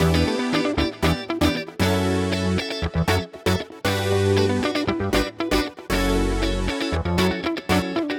11 Backing PT3.wav